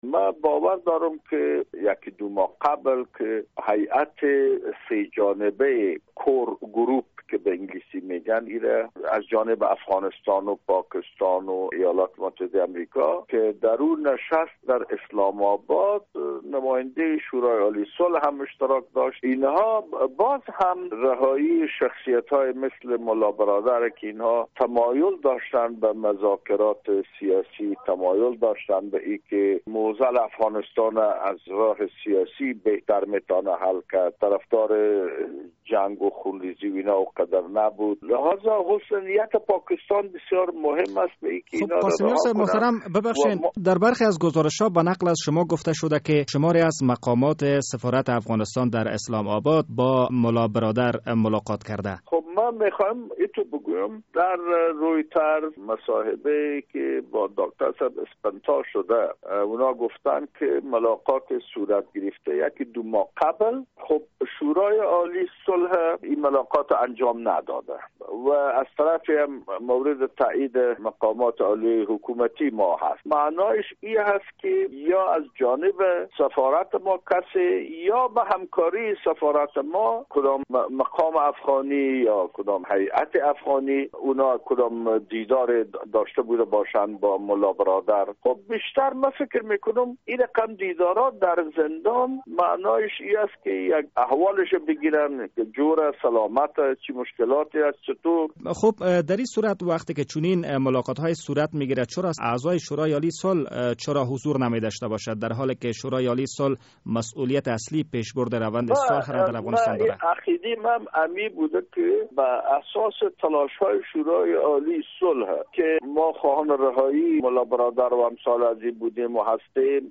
مصاحبه در مورد ملاقات اعضای شورای صلح با ملا برادر در زندان